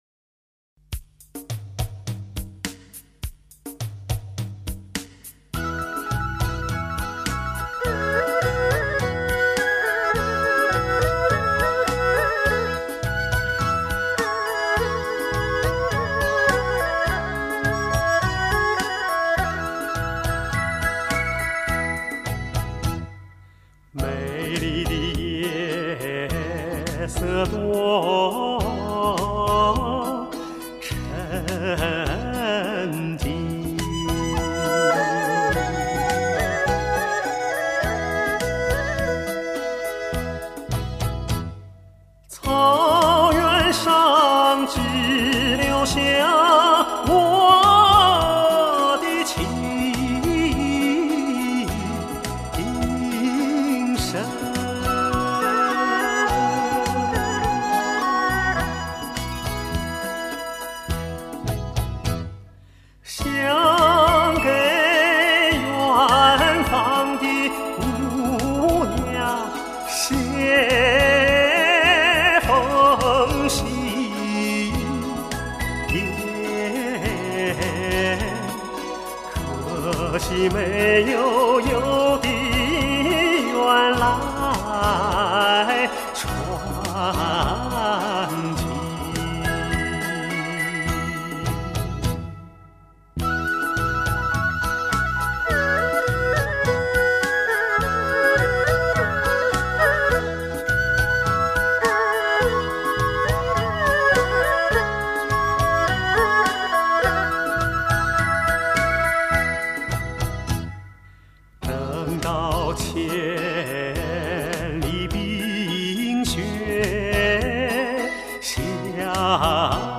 他的演唱博采众长，音色纯正透亮，音域宽广，声音流畅，感情淳朴真挚，洒脱中见细腻，轻柔中蕴深情，是当今中国歌坛的佼佼者。